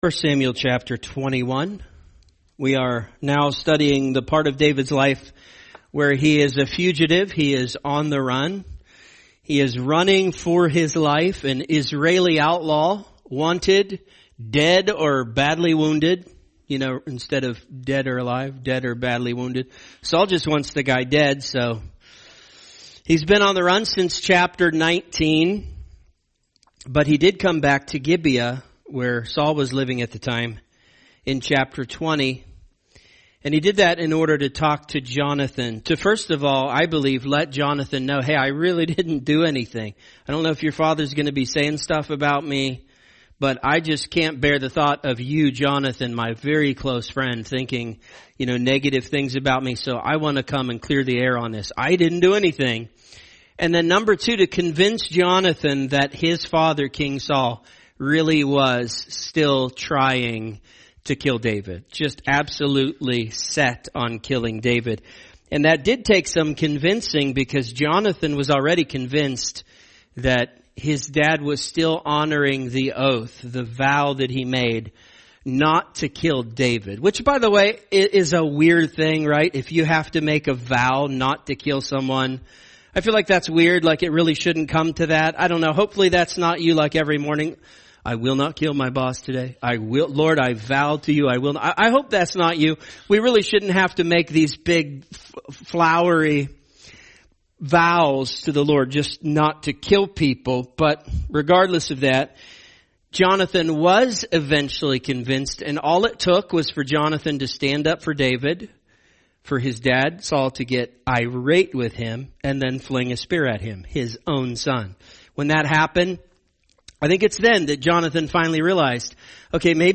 A message from the topics "The Book of 1 Samuel."